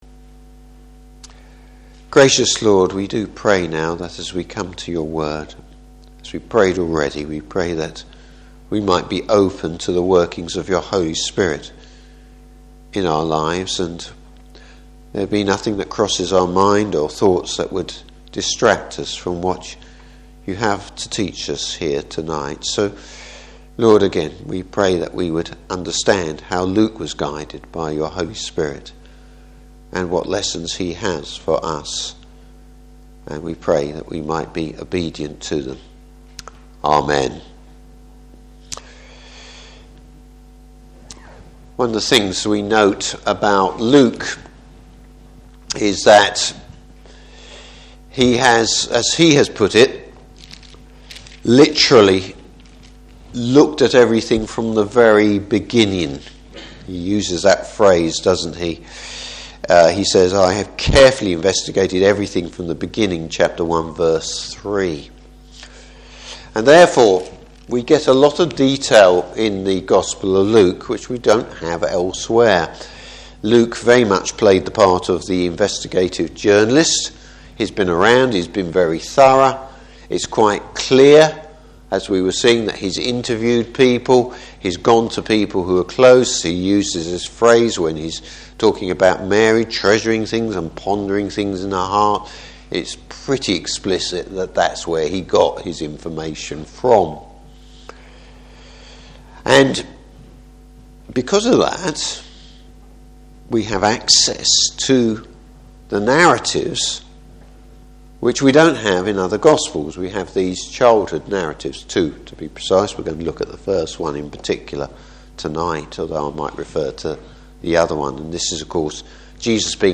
Service Type: Evening Service How Jesus fulfills God’s Law and His promises.